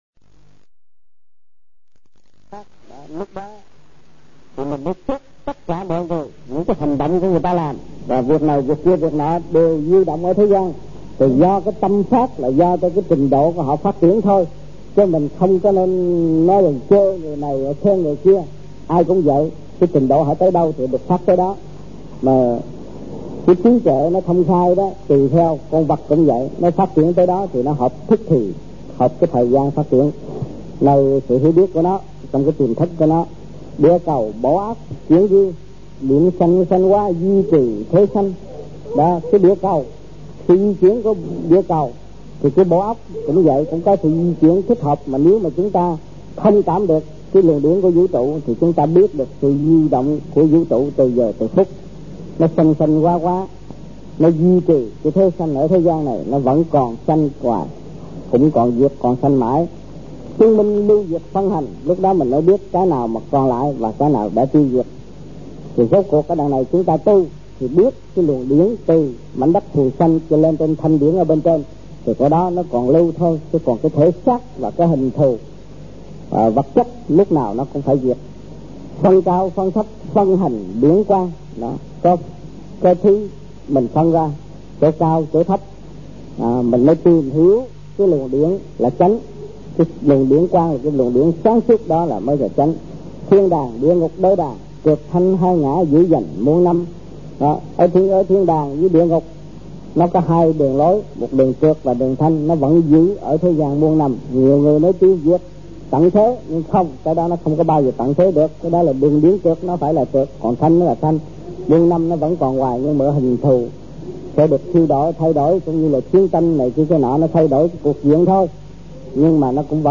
Việt Nam Trong dịp : Sinh hoạt thiền đường >> wide display >> Downloads